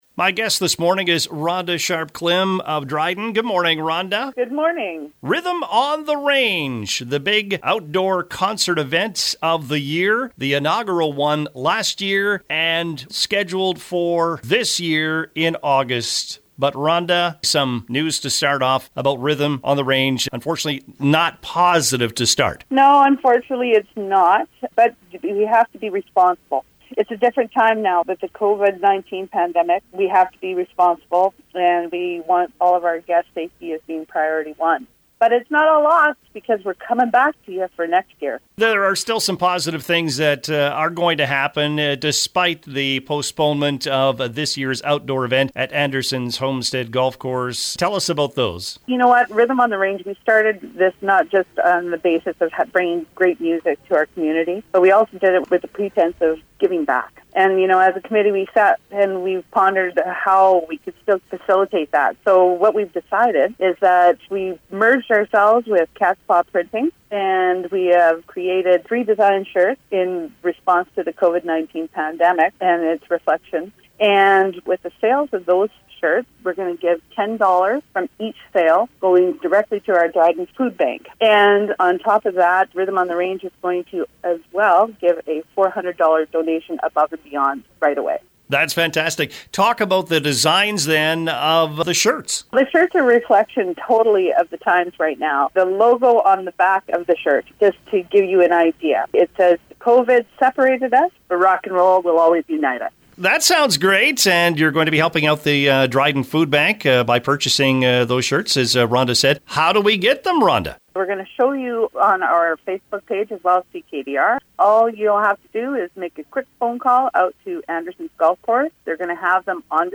a guest on the CKDR Morning Show